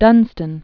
(dŭnstən), Saint 909?-988.